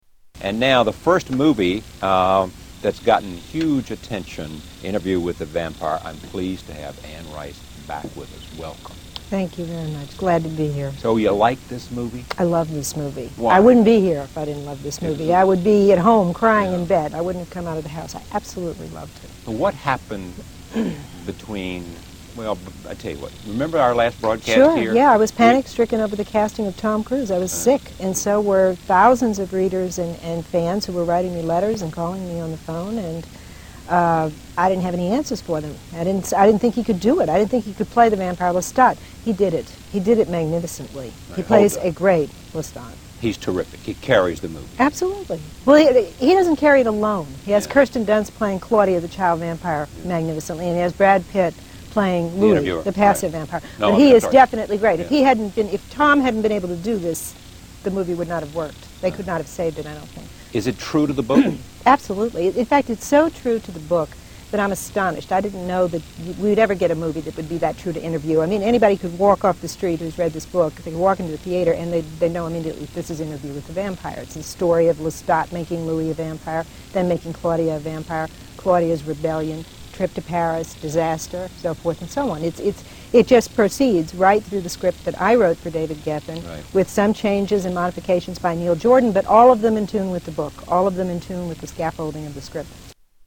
Interview with Charlie Rose